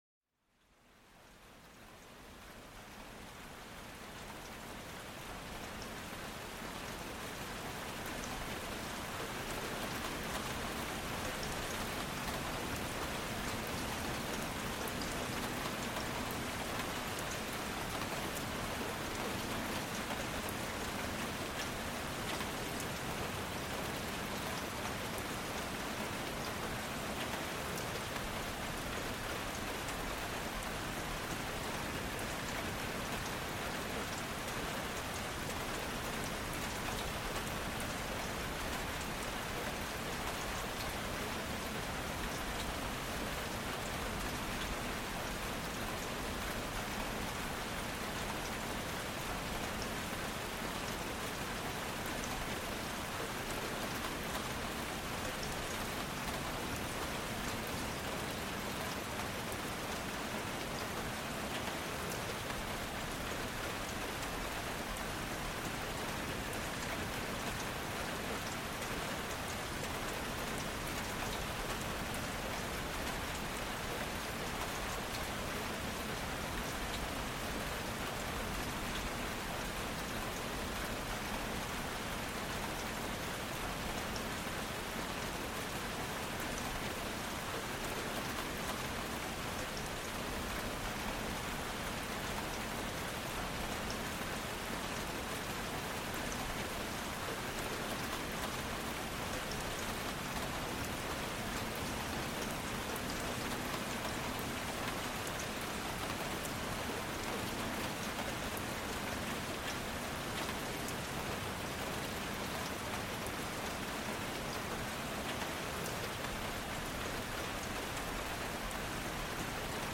Pluie Battante: Apaisement Naturel et Détente Profonde